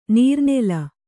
♪ nīrnela